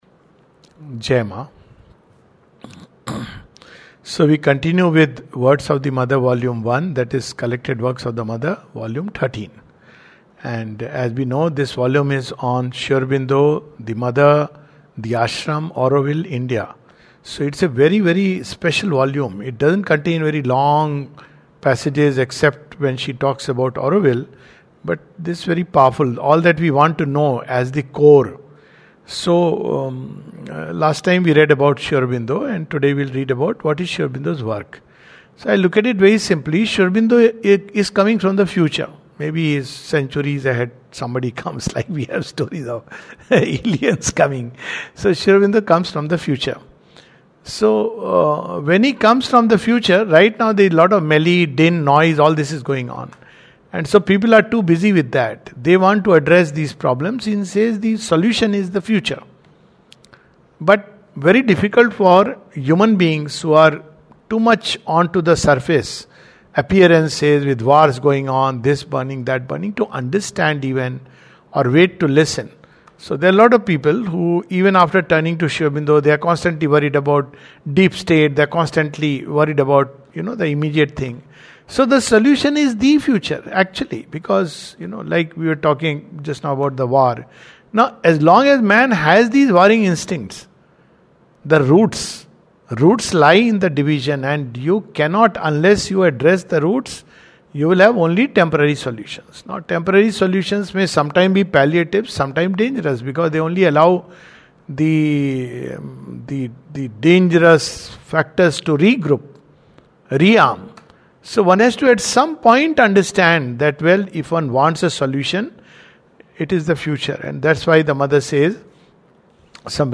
This is a continuation of reading from CWM 13. A talk